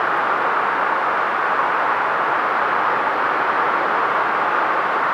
wind_howl.wav